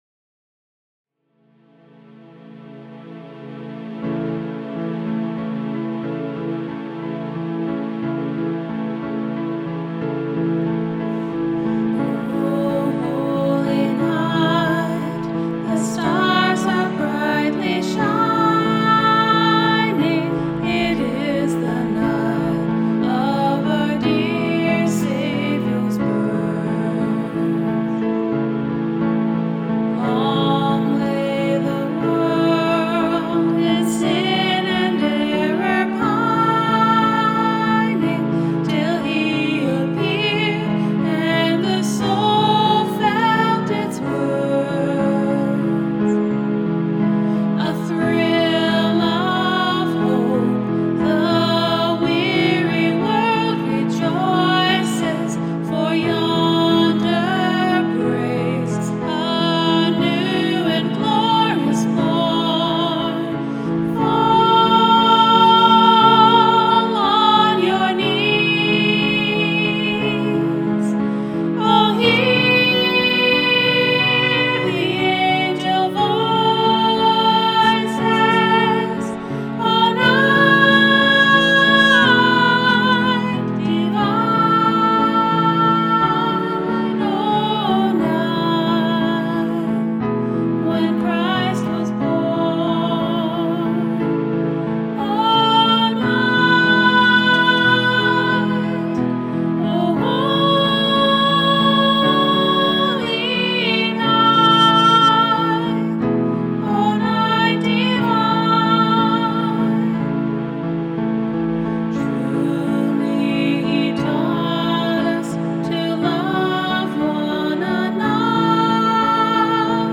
LISTEN TO THE SOME CHRISTMAS FAVOURITES RECORDED BY OUR WORSHIP TEAM!